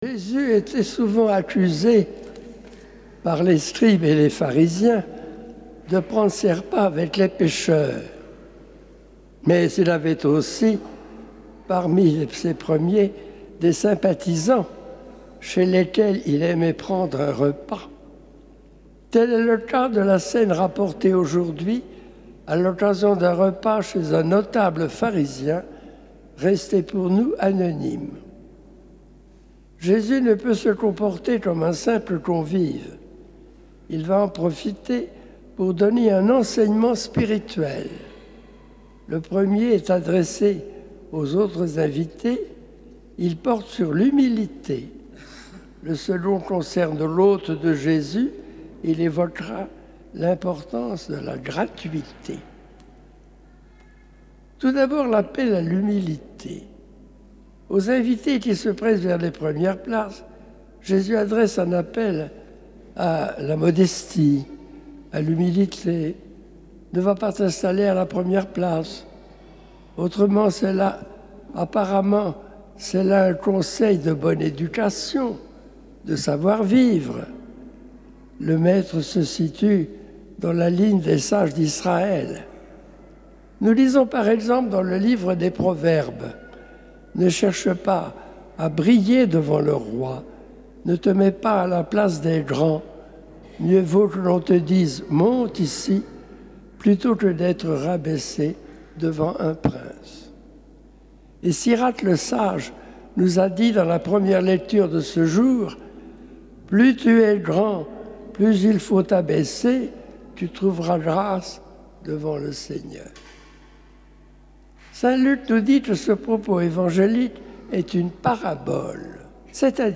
Catégories homélies